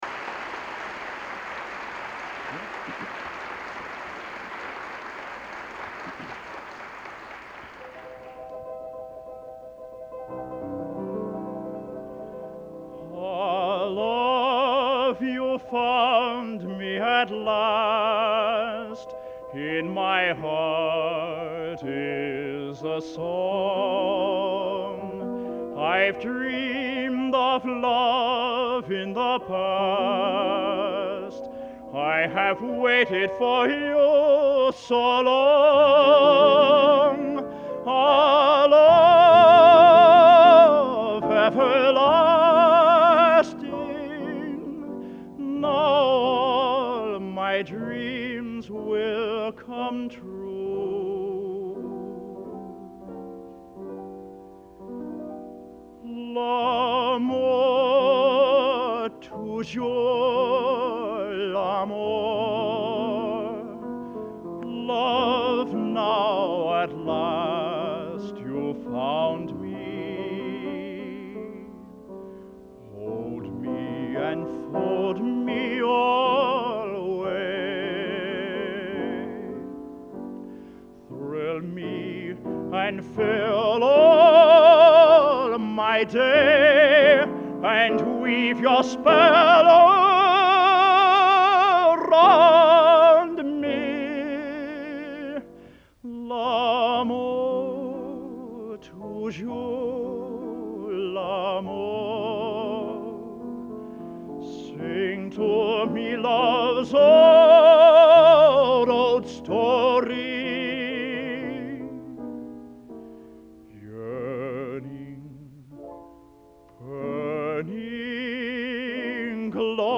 Location: West Lafayette, Indiana
Genre: | Type: End of Season |Featuring Hall of Famer